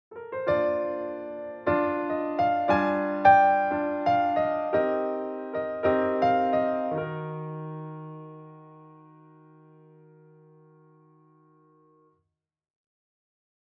ピアノソロのジングルです。
ゆったりリラックスした雰囲気を演出する際に使えそうな曲かなと思います。
This is a piano solo jingle.